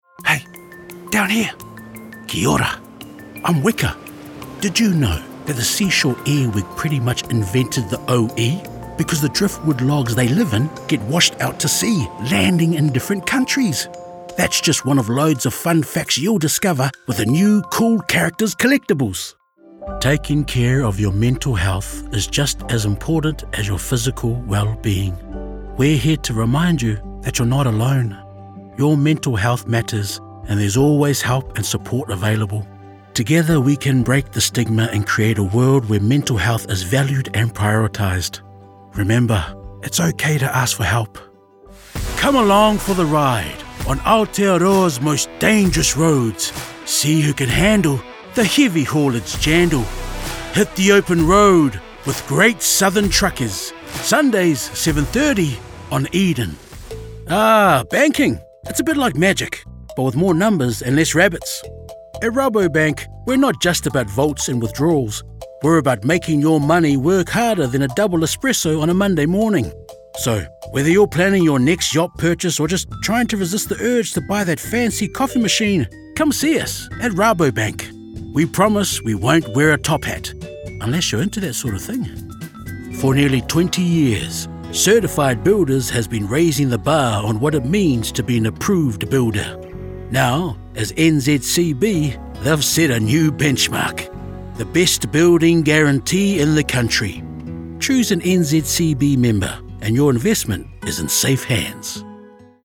Accents: Canadian Canadian Eastern European Filipino Spanish - Iberian